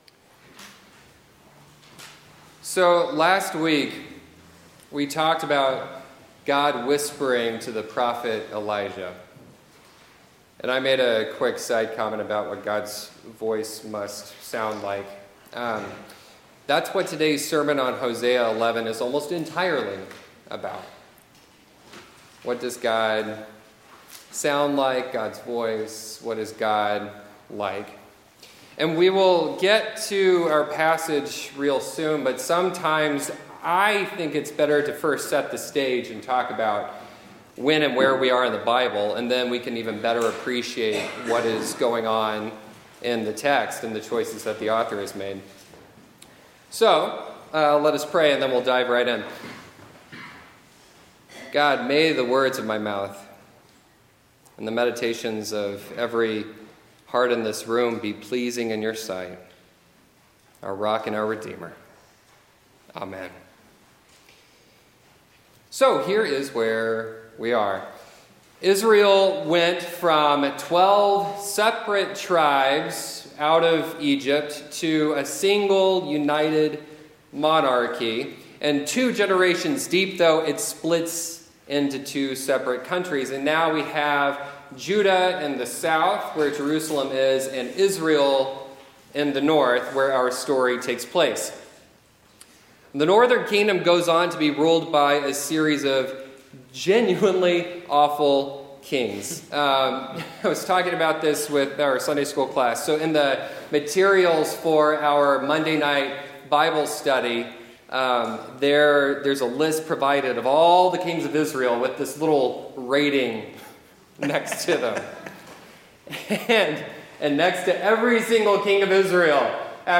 Scripture Lesson